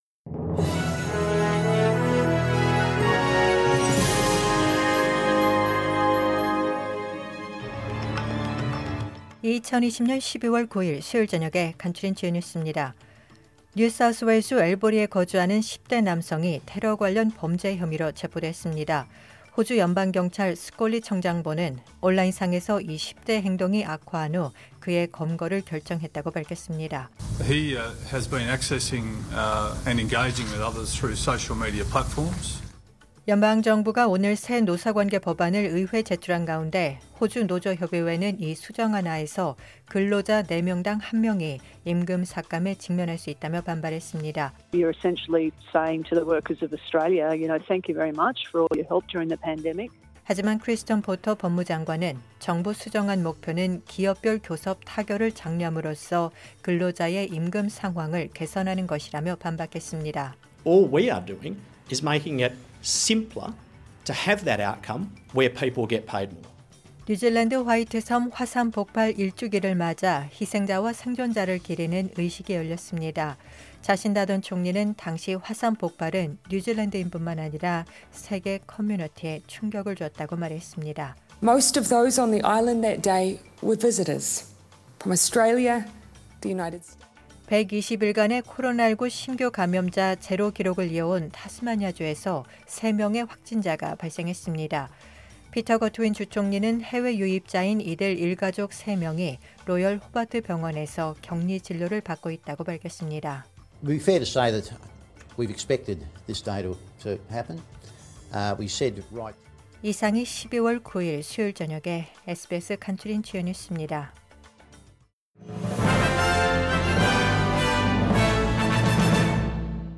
SBS News Outlines...2020년 12월 9일 저녁 주요 뉴스
2020년 12월 9일 수요일 저녁의 SBS 뉴스 아우트라인입니다.